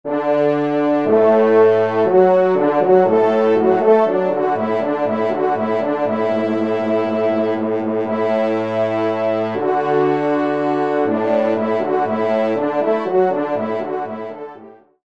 Pupitre de Basse